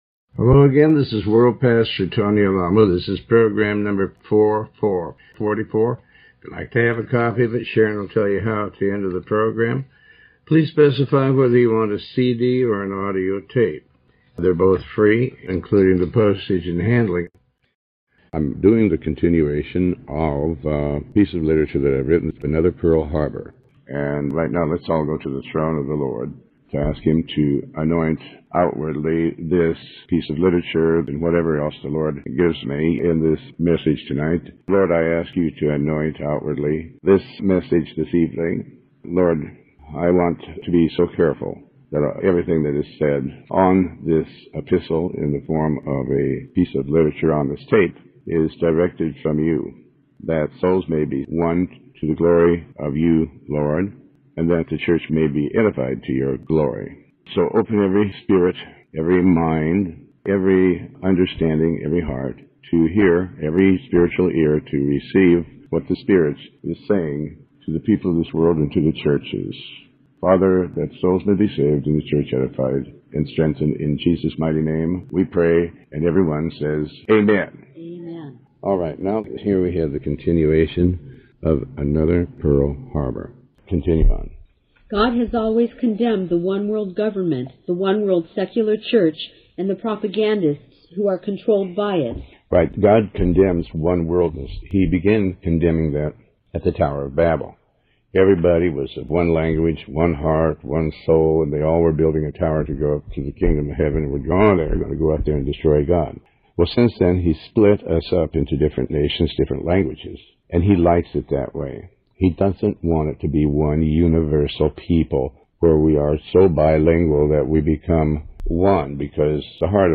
Talk Show Episode
Show Headline Tony Alamo Show Sub Headline Pastor Tony Alamo Another Pearl Harbor Part 2 Tony Alamo with Tony Alamo World Wide Ministries Pastor Tony Alamo Another Pearl Harbor Part 2 #044 B In this program originally aired in 2005, Pastor Alamo has read, and comments on a piece of Gospel literature that he wrote entitled, "Another Pearl Harbor." This is an exposé regarding the end times.